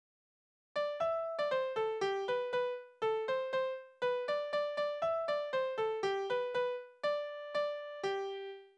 Kindertänze
Tonart: G-Dur
Taktart: 2/4
Tonumfang: große Sexte
Besetzung: vokal